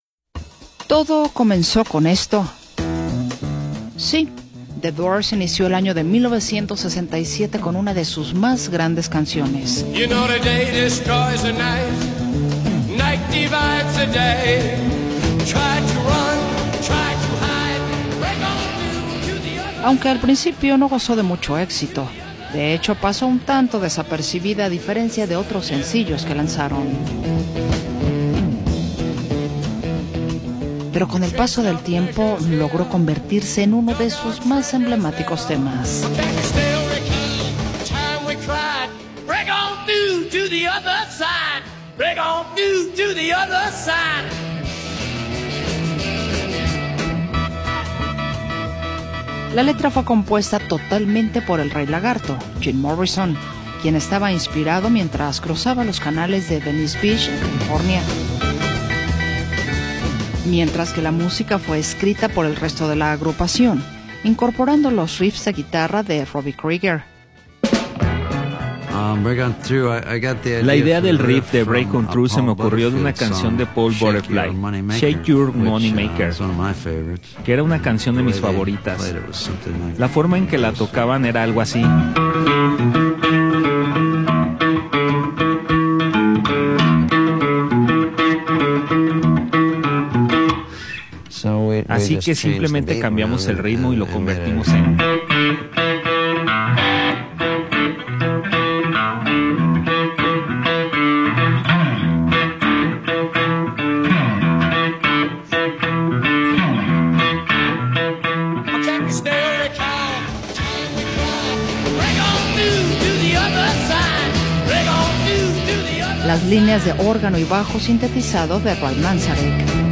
la canción introduce la voz provocadora